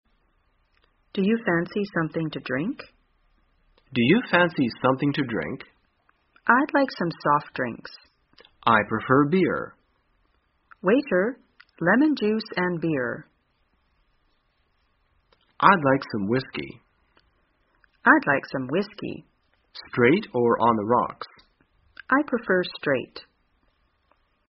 在线英语听力室生活口语天天说 第269期:怎样点酒水?的听力文件下载,《生活口语天天说》栏目将日常生活中最常用到的口语句型进行收集和重点讲解。真人发音配字幕帮助英语爱好者们练习听力并进行口语跟读。